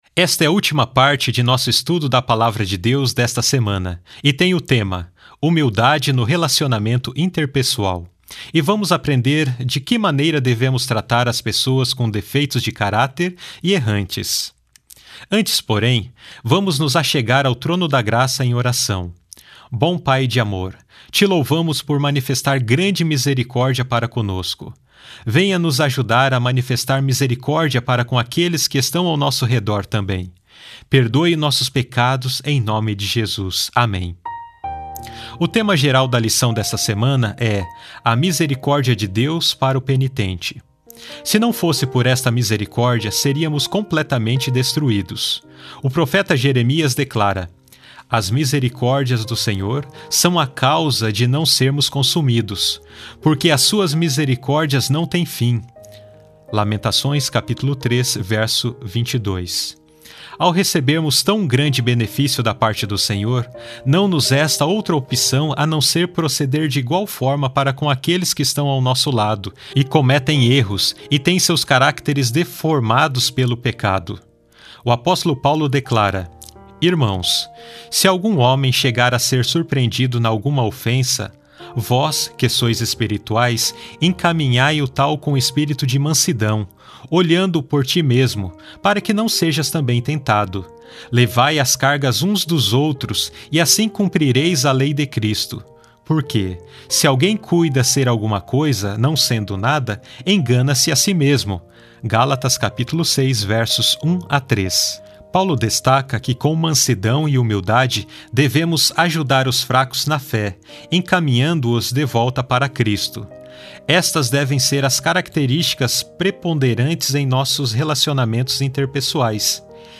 Áudios - Lição em Áudio